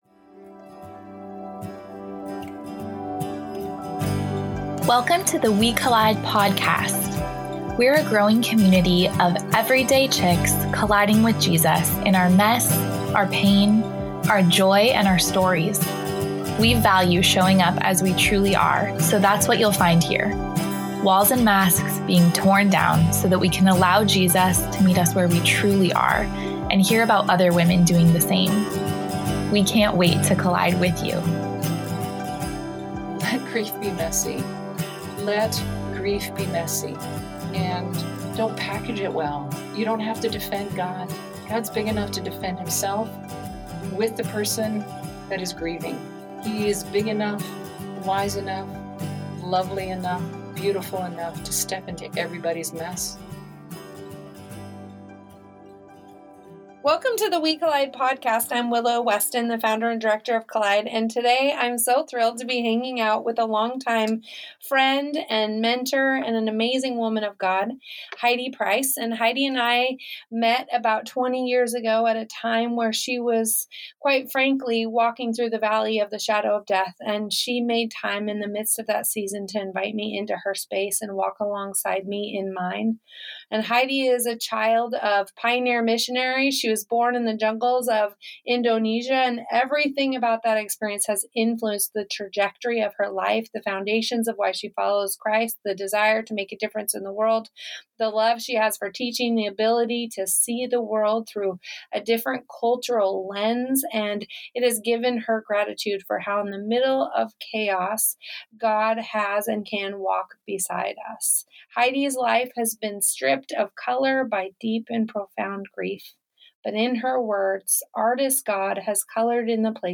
We are excited to share our conversation with an inspirational woman about the impact of grief and the journey toward healing.